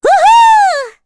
Rehartna-Vox_Happy5_kr.wav